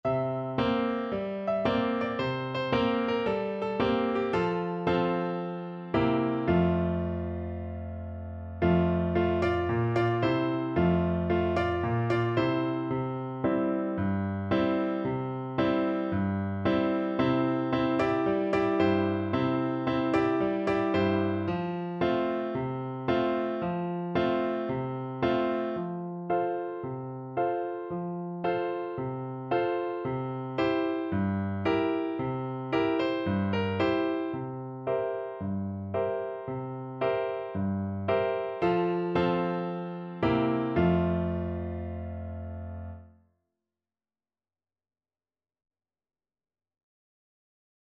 4/4 (View more 4/4 Music)
Cheerfully! =c.112
Traditional (View more Traditional French Horn Music)